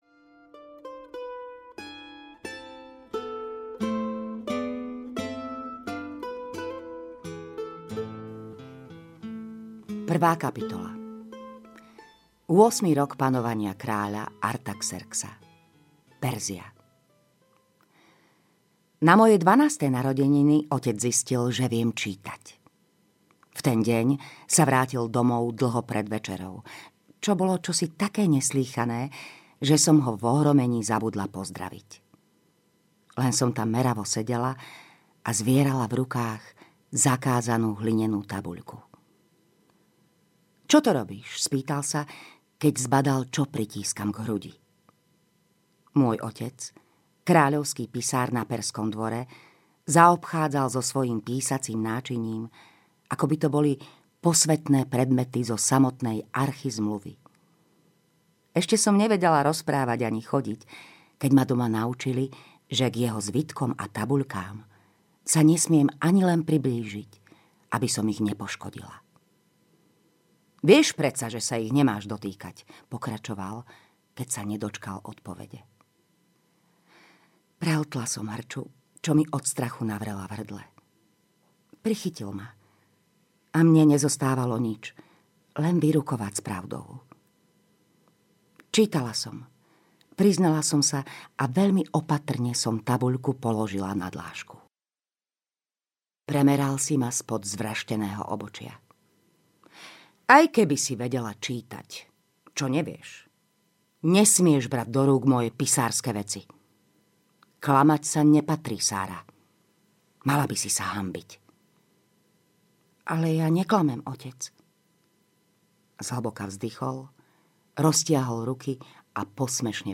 Rubínová žatva audiokniha
Ukázka z knihy